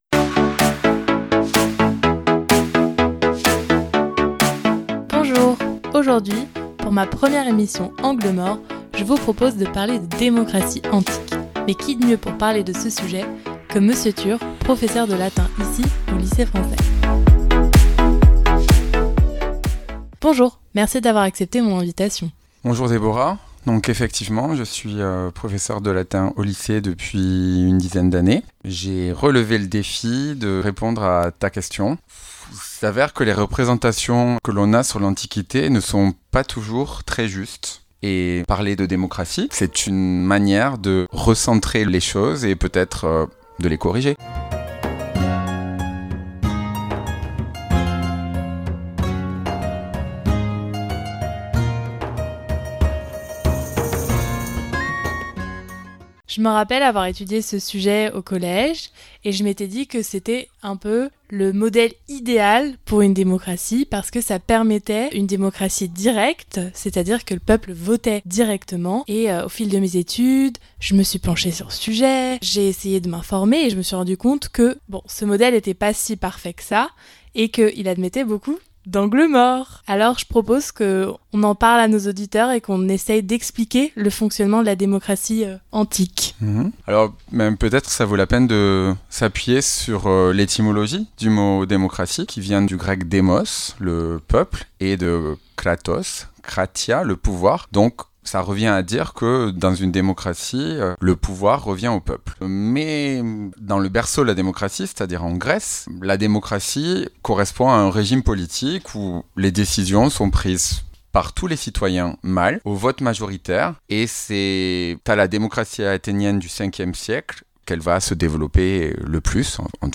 ANGLES MORTS .Podcast sur un sujet de société avec un invité pour chaque thème différent. Le principe : Sortir des sentiers battus et discuter de thèmes mis de côté.